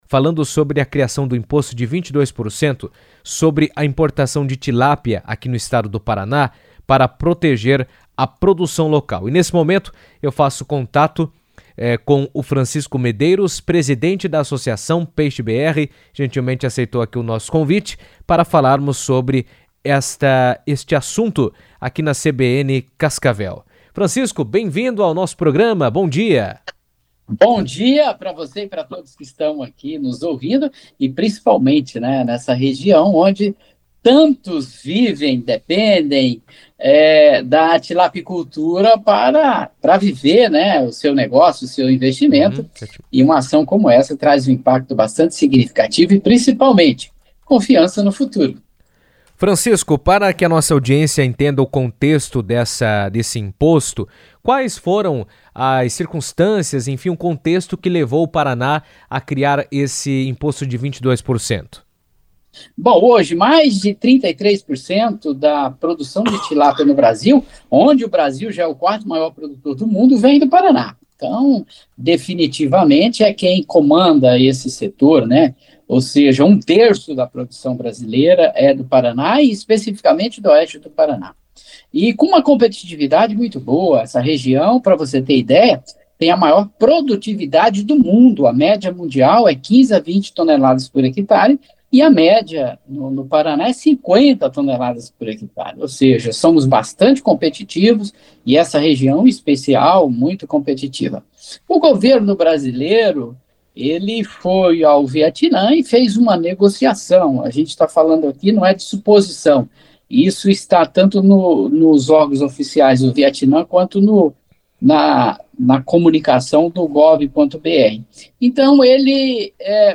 O Paraná passou a adotar uma alíquota de 22% sobre a importação de tilápia, conforme previsto em nova legislação estadual que altera as regras para a entrada do pescado no mercado local. A medida foi tema de entrevista na CBN